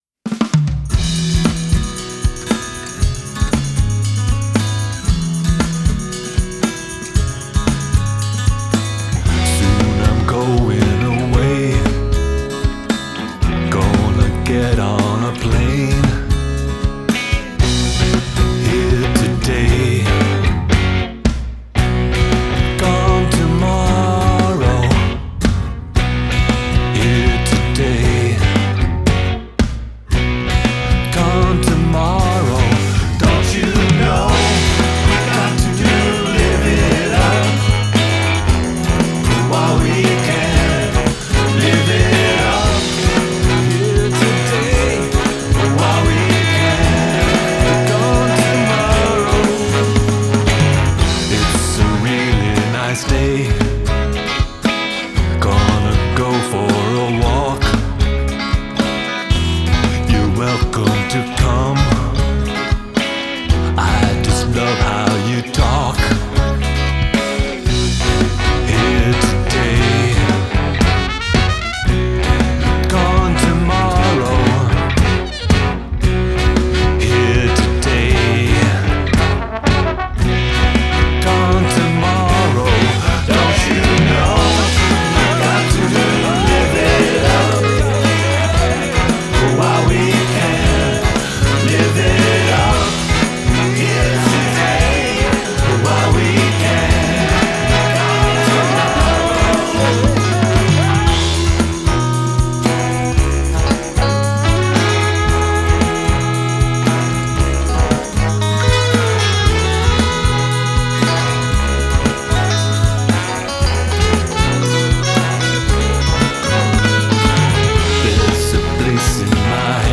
vocals, acoustic guitars, electric guitars
alto saxophone
baritone saxophone
backing vocals